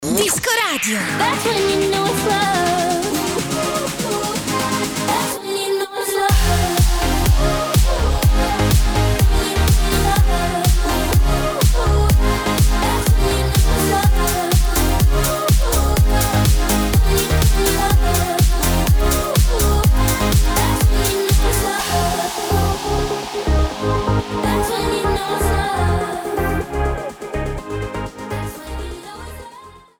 la bella cantante e cantautrice country americana.